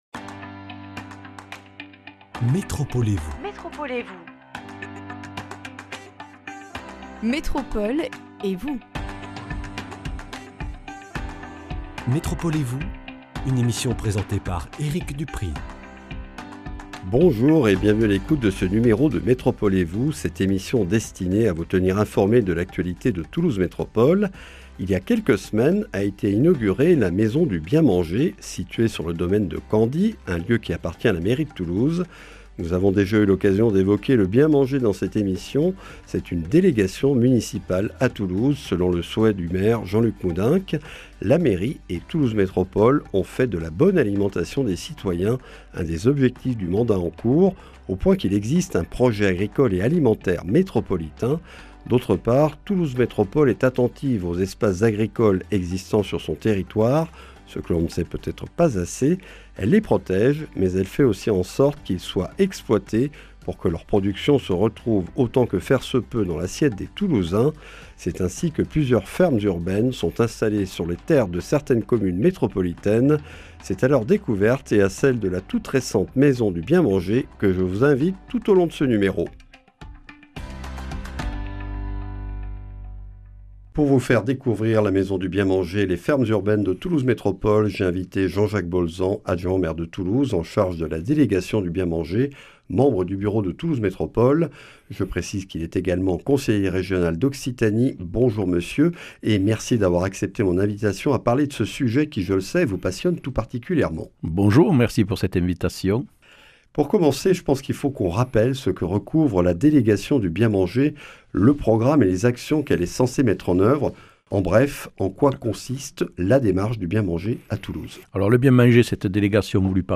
La Maison du Bien Manger a été inaugurée il y a quelques semaines au domaine de Candie, propriété de la mairie de Toulouse. Jean-Jacques Bolzan, adjoint au maire de Toulouse délégué au "Bien manger", membre du bureau de Toulouse Métropole et conseiller régional d’Occitanie, nous présente ce nouveau lieu ouvert au public, véritable vitrine du projet agricole et alimentaire de Toulouse Métropole, avec sa cuisine pédagogique et ses 5 salles polyvalentes dédiées à des conférences, séminaires, ateliers et à l’événementiel, puis les fermes urbaines situées sur le territoire métropolitain.